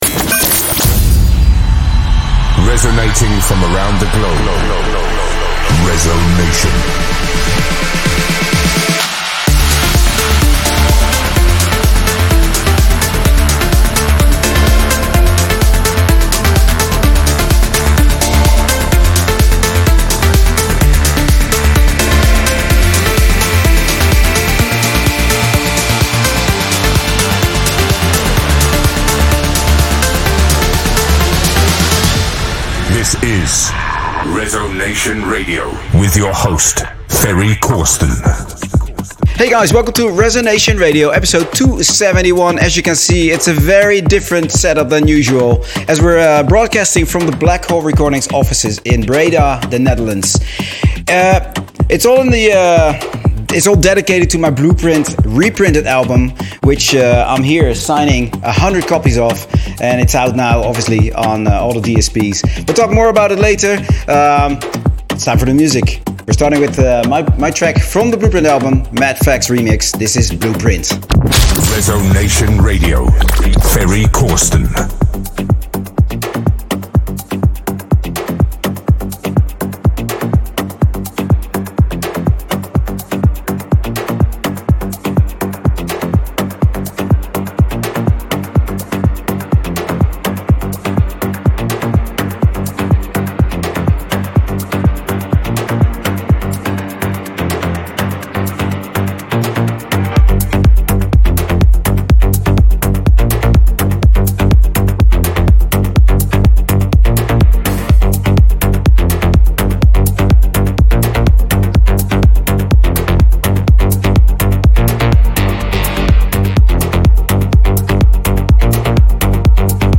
music DJ Mix in MP3 format
Genre: Trance